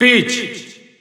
Italian Announcer announcing Peach.
Peach_Italian_Announcer_SSBU.wav